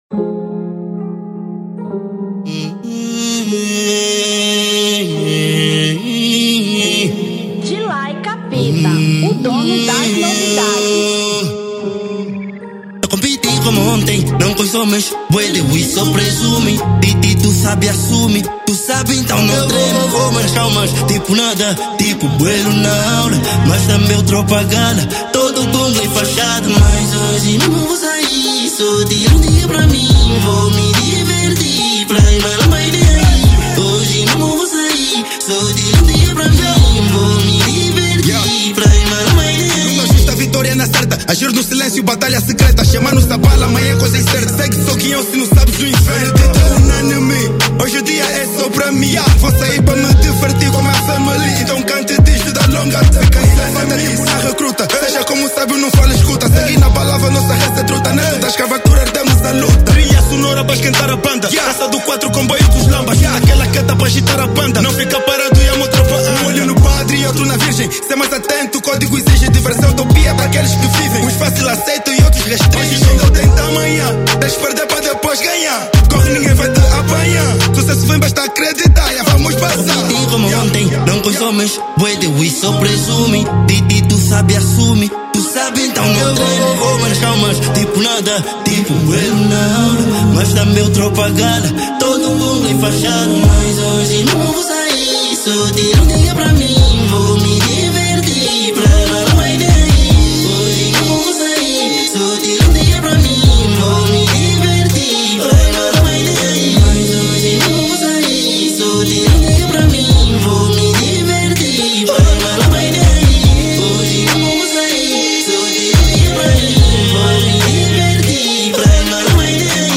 Rap 2025